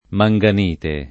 manganite [ ma jg an & te ] s. f. (min.)